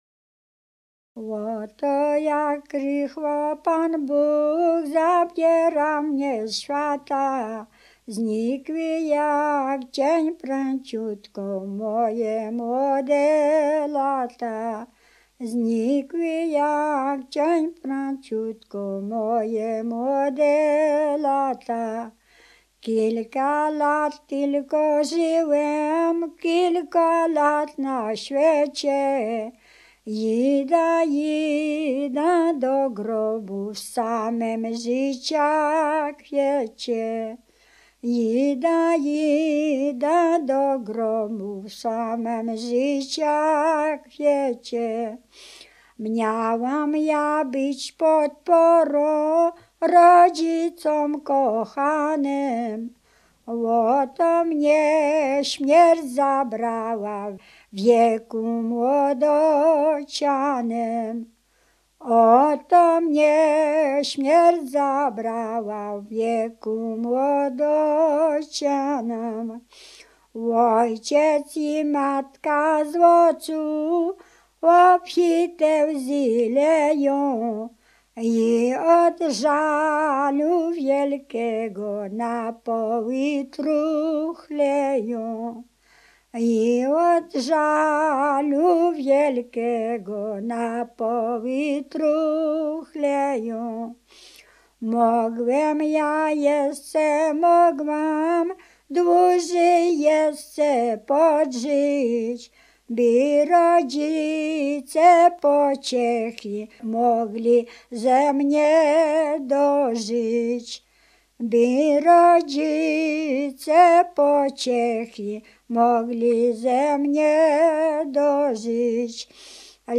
Kurpie
Pogrzebowa
pogrzebowe katolickie nabożne